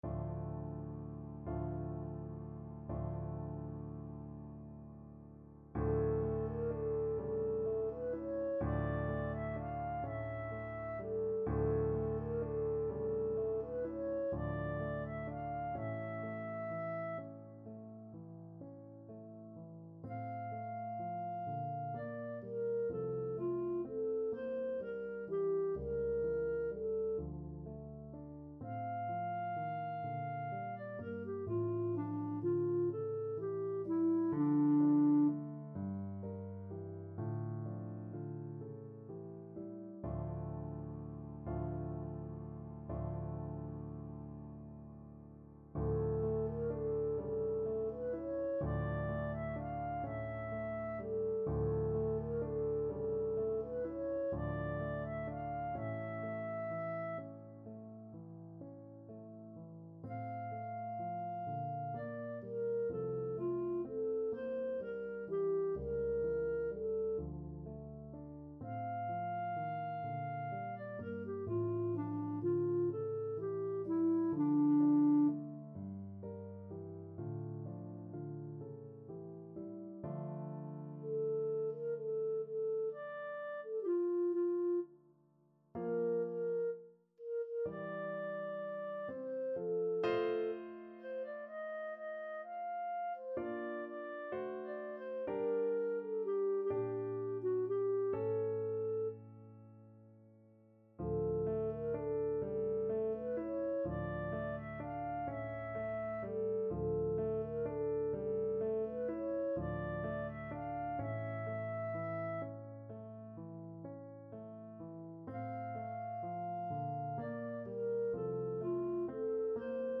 Clarinet
6/8 (View more 6/8 Music)
= 42 Andante con moto (View more music marked Andante con moto)
D minor (Sounding Pitch) E minor (Clarinet in Bb) (View more D minor Music for Clarinet )
Classical (View more Classical Clarinet Music)